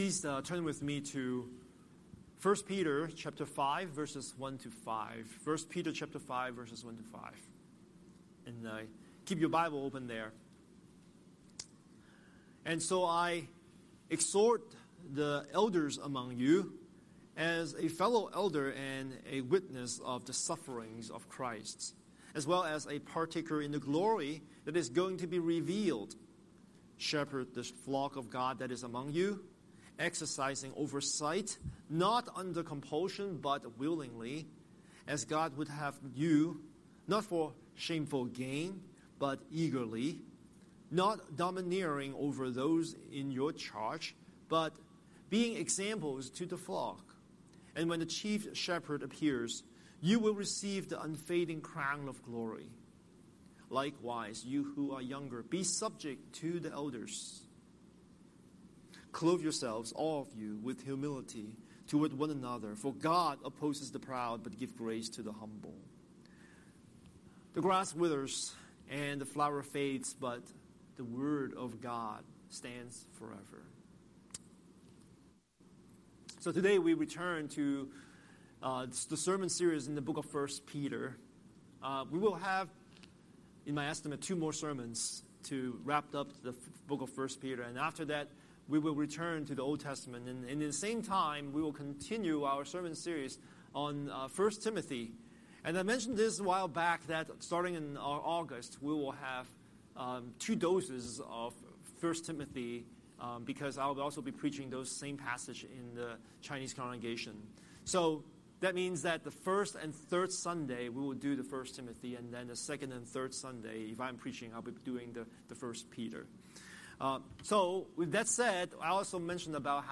Scripture: 1 Peter 5:1–5a Series: Sunday Sermon